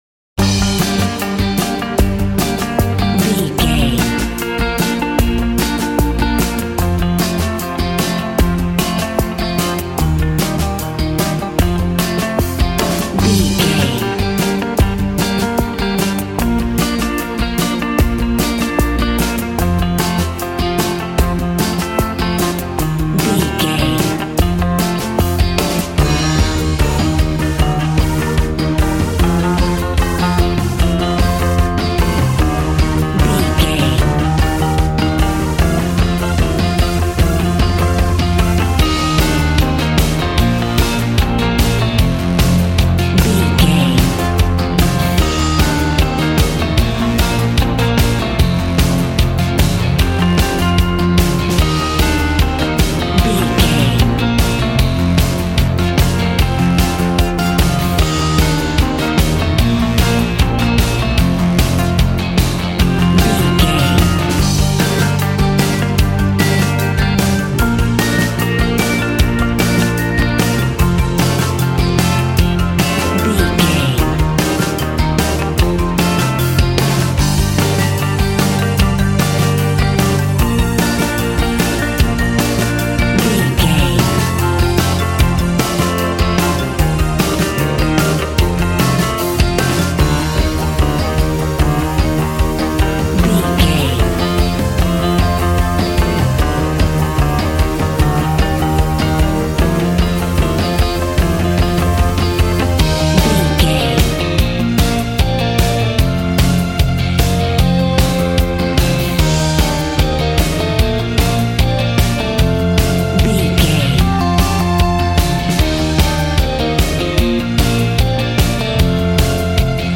Ionian/Major
groovy
powerful
fun
organ
drums
bass guitar
electric guitar
piano